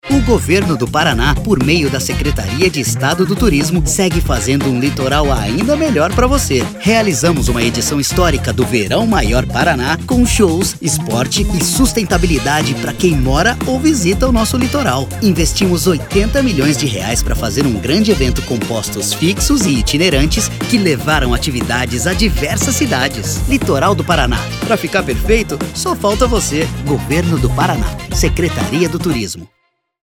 spot_de_30_-_prestacao_de_contas.mp3